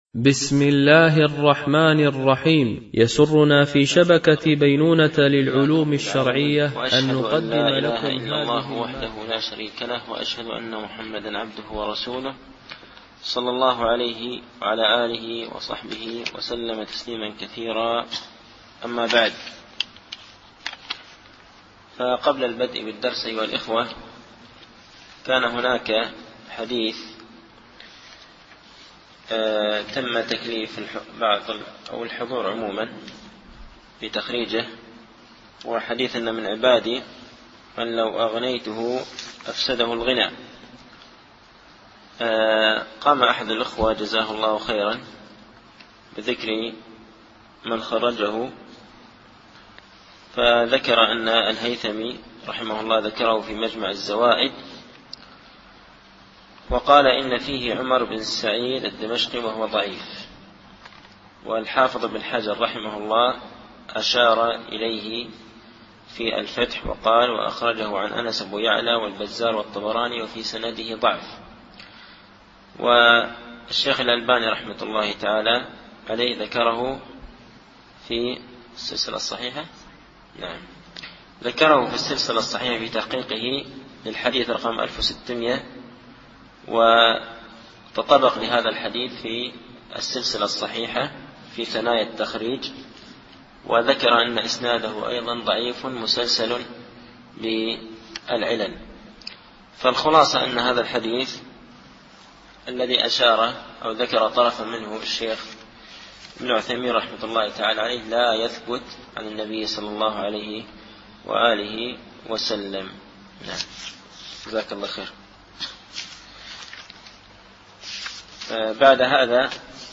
التعليق على القول المفيد على كتاب التوحيد ـ الدرس الخامس و الثلاثون